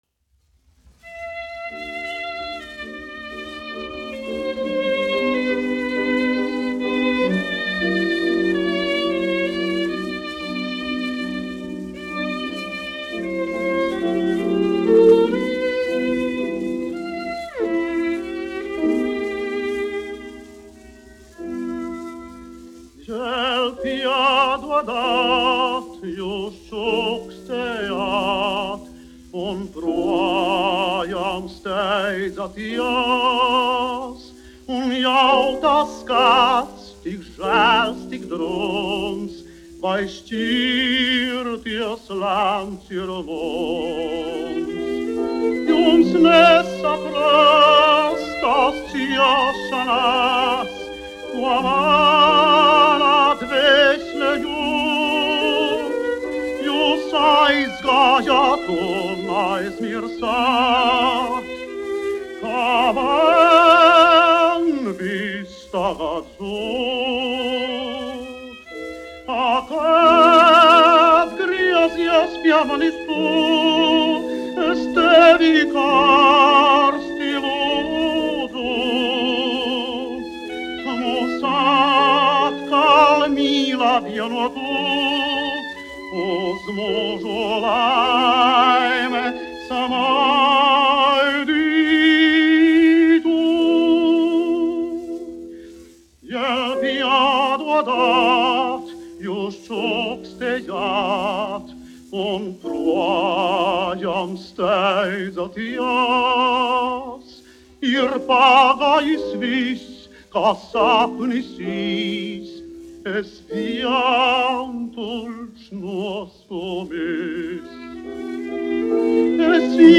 1 skpl. : analogs, 78 apgr/min, mono ; 25 cm
Dziesmas (augsta balss) ar instrumentālu ansambli
Skaņuplate